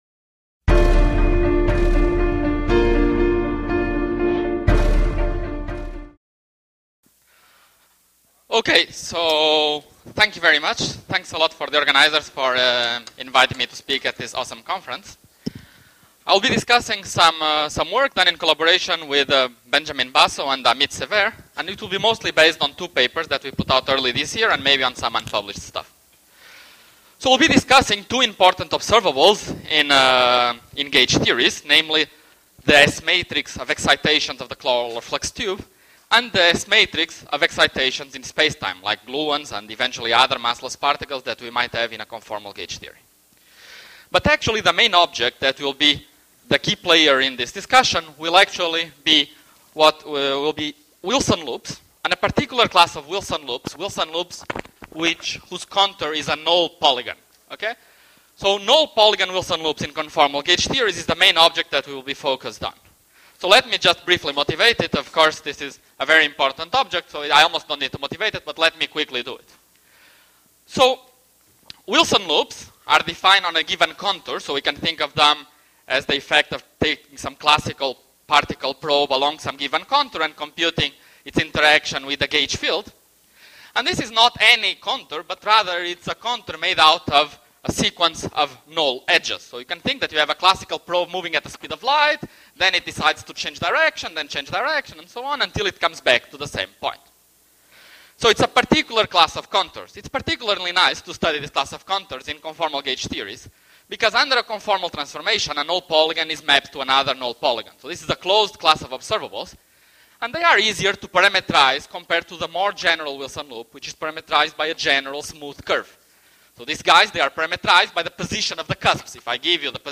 Video Videos of the talks 27(Thu), June 2013 2.
Location: Sogang University, Seoul